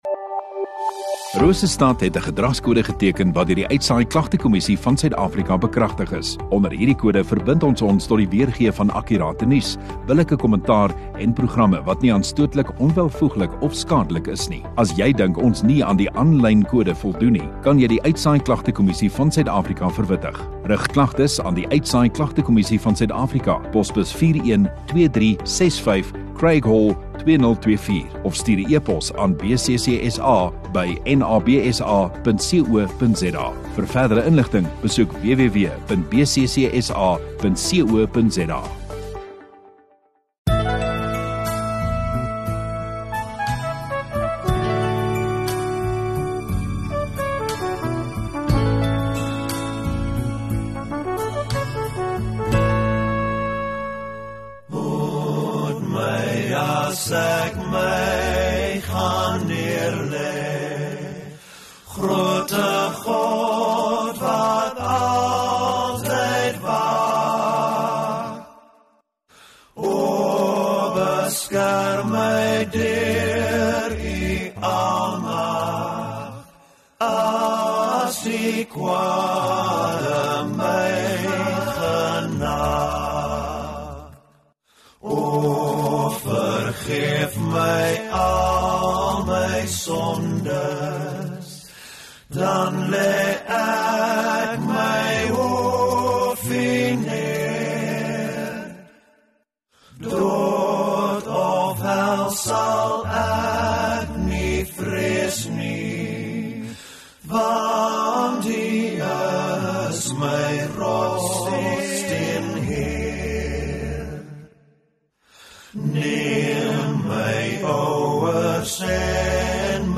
4 May Sondagaand Erediens